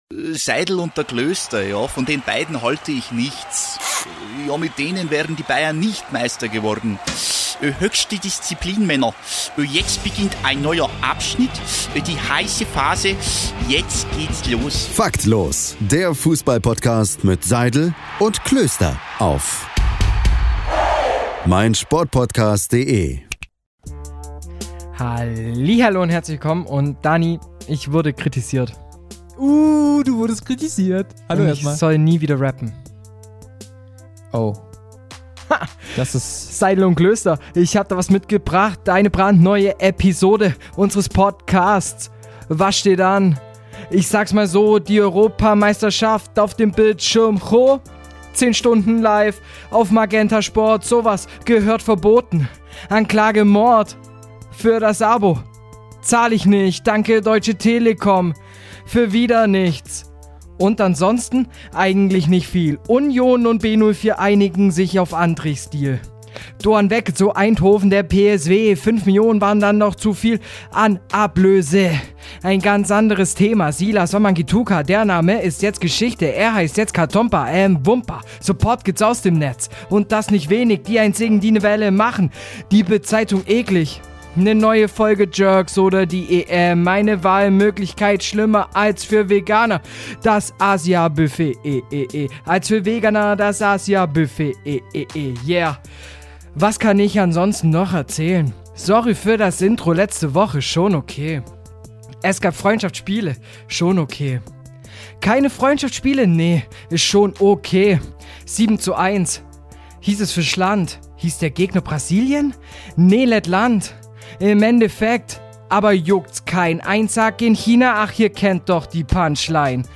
2 Männer – 1 Aufgabe: Das Geschehen der vergangenen Wochen knallhart und kompetent mit viel Witz auf den Punkt zu bringen, aber eben „Faktlos“ und im Dialog.